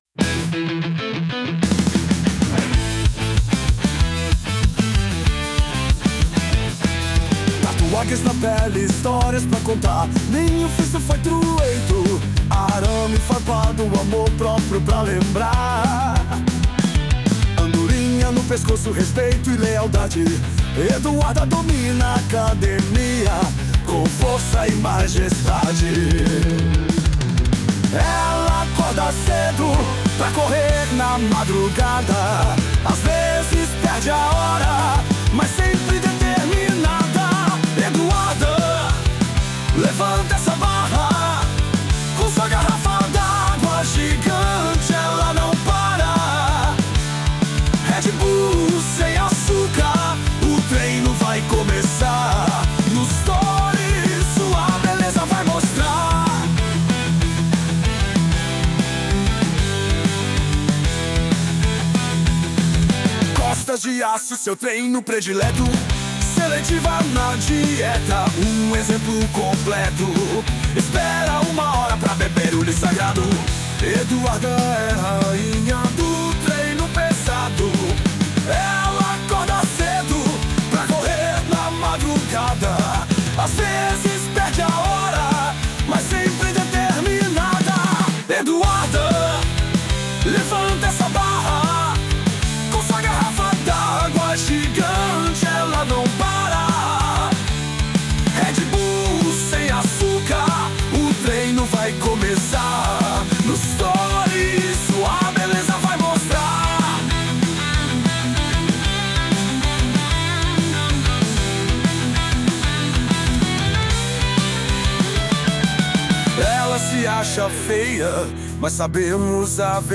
Versão Alternativa 2